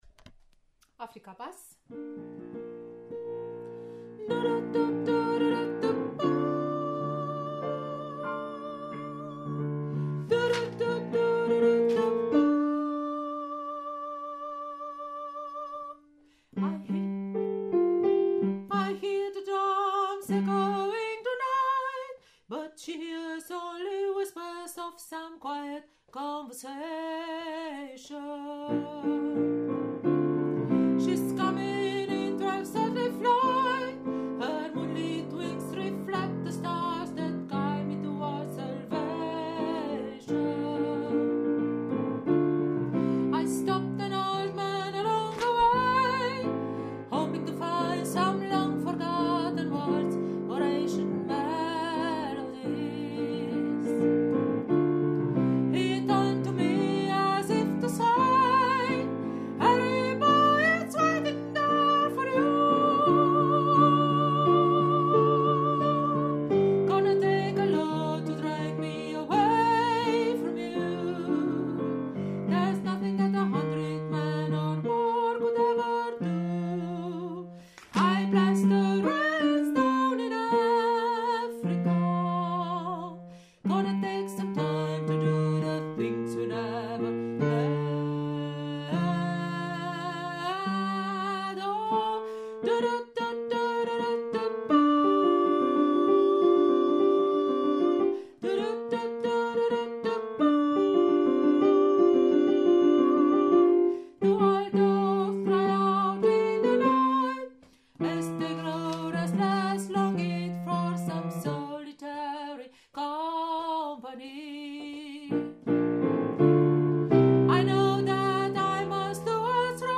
Africa – Bass
Africa-Bass.mp3